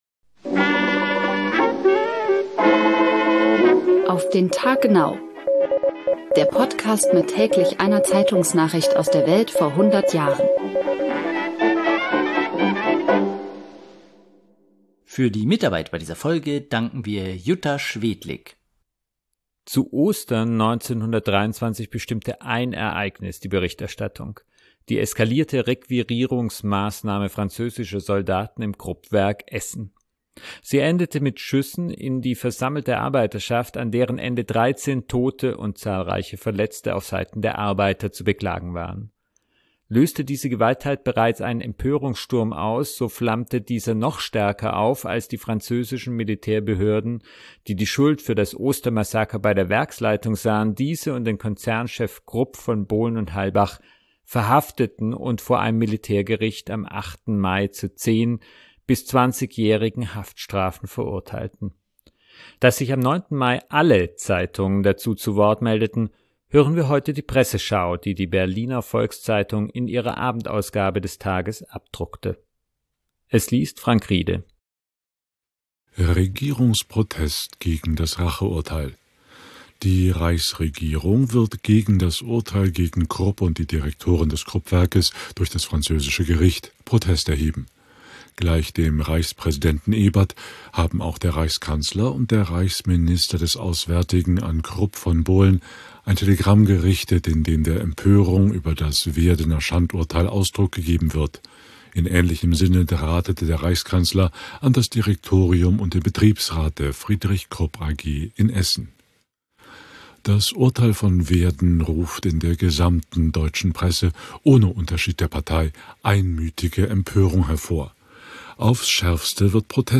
Da sich am 9. Mai alle Zeitungen dazu zu Wort meldeten, hören wir heute die Presseschau, die die Berliner Volks-Zeitung in ihrer Abendausgabe des Tages abdruckte.